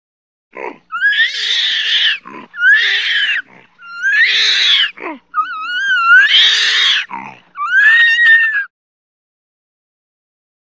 Звук кричащего орангутана